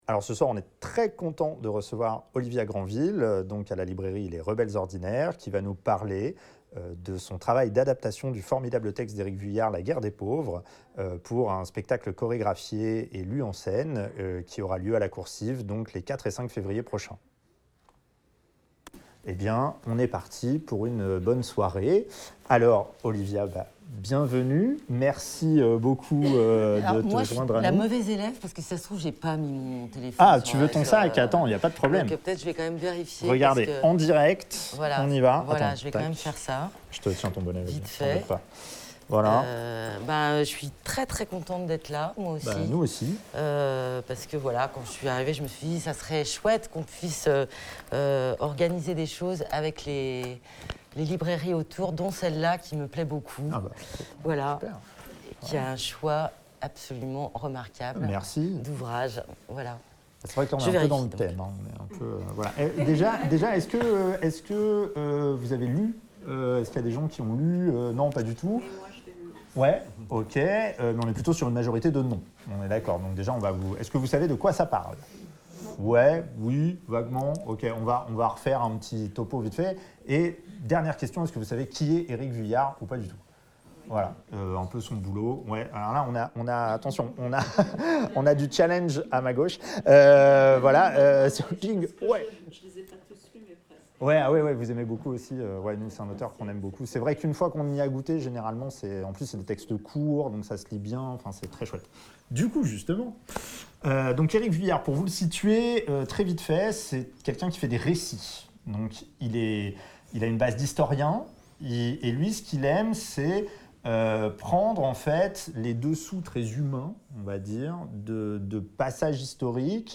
invitée à la librairie rochelaise Les Rebelles Ordinaires